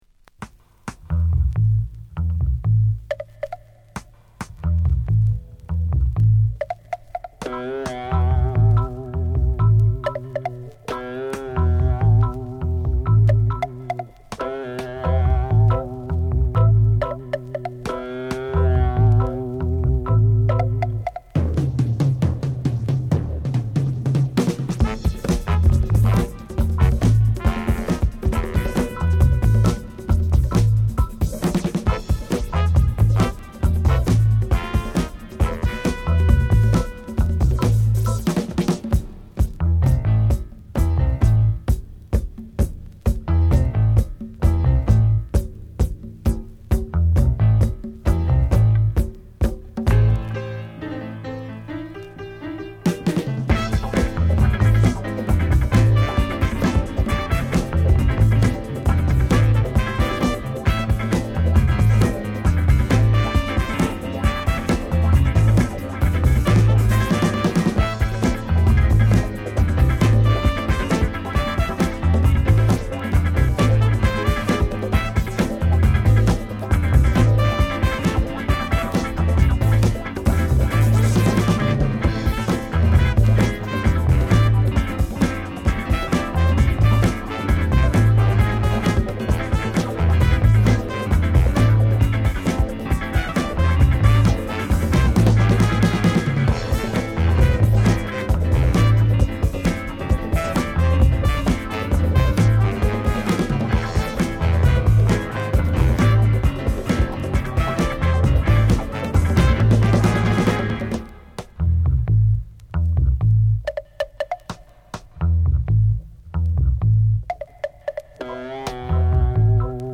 Some good beats
pop fuzz
plus the groovy pop
In excellent shape, only slightly warp.